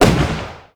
EXPLOSION_Arcade_12_mono.wav